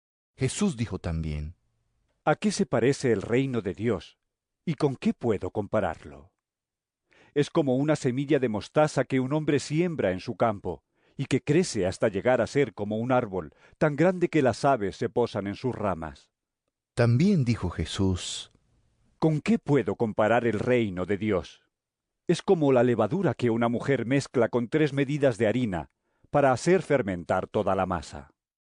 Lc 13 18-21 EVANGELIO EN AUDIO